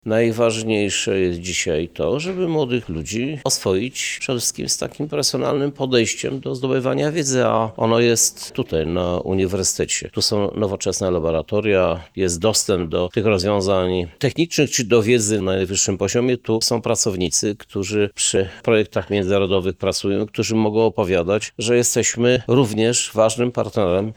O przedsięwzięciu i jego znaczeniu mówi prezydent miasta Lublin dr. Krzysztof Żuk: